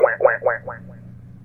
Duck Game Quack Button
Duck-Game-Quack-Button.mp3